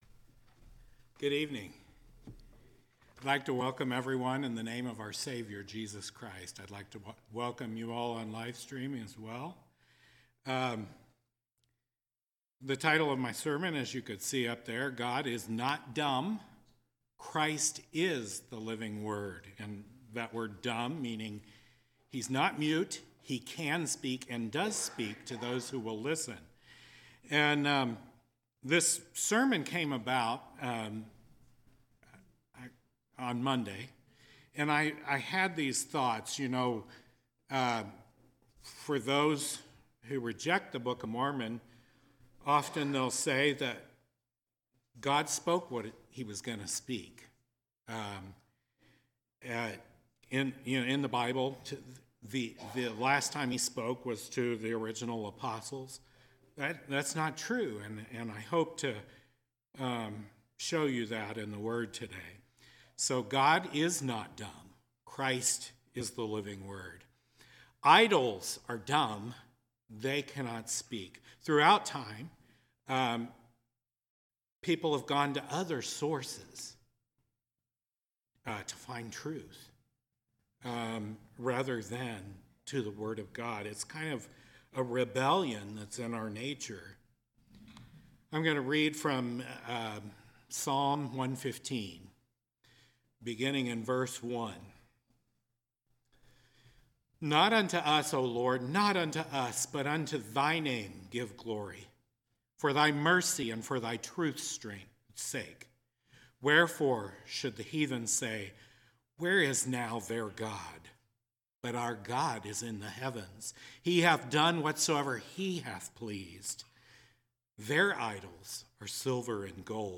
1/15/2023 Location: Temple Lot Local Event